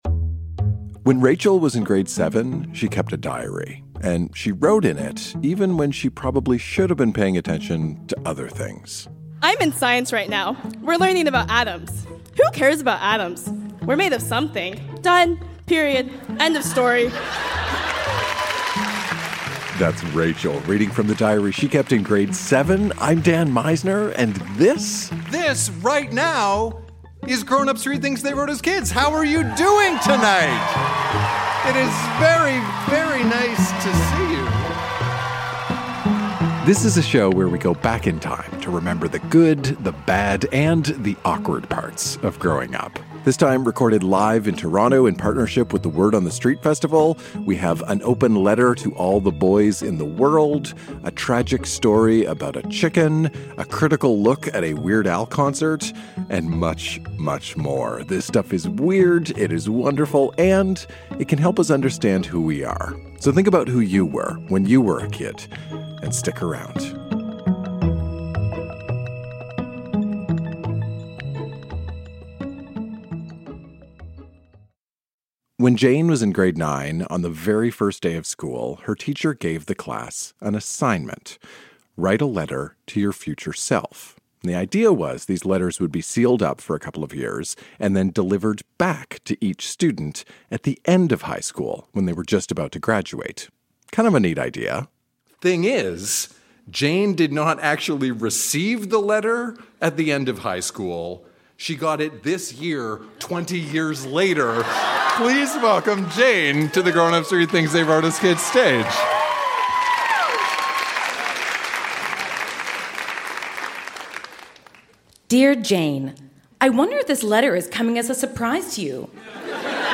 Recorded live at the Royal Cinema in Toronto in partnership with The Word on the Street Festival.